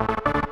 synth_gat-08.ogg